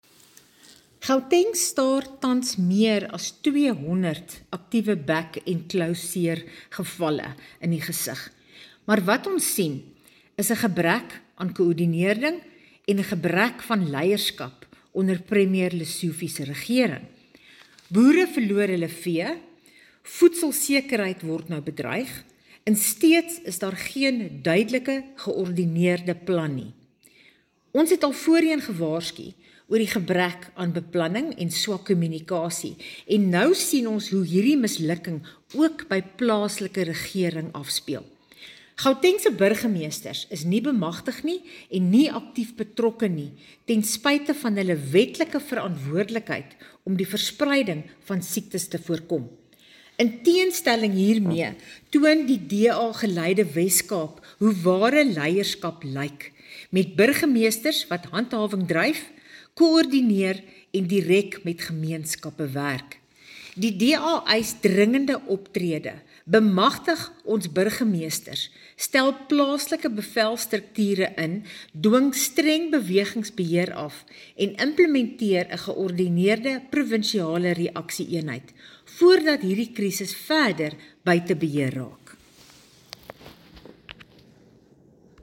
Afrikaans soundbites by Bronwynn Engelbrecht MPL.